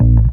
B_07_Bass_01_SP.wav